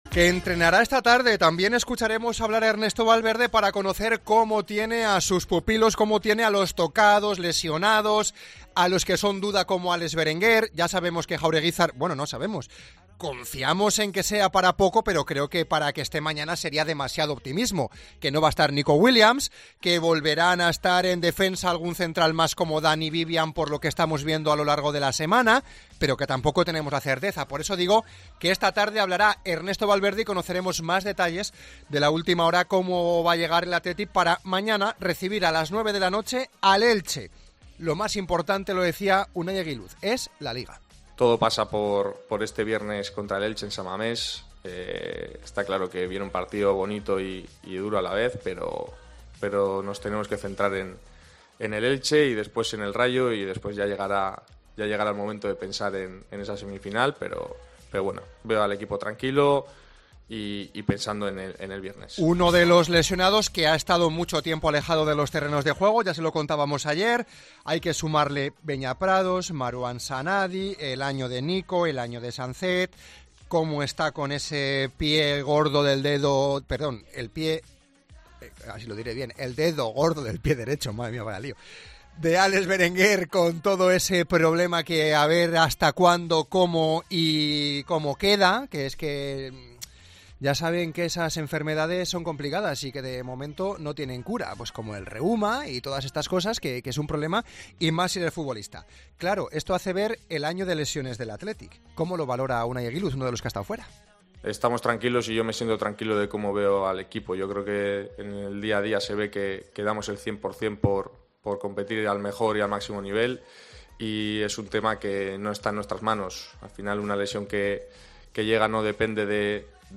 En el programa 'Deportes en Herrera en Cope', el defensa Unai Egiluz ha insistido en aparcar la euforia de la Copa del Rey para centrarse en el presente.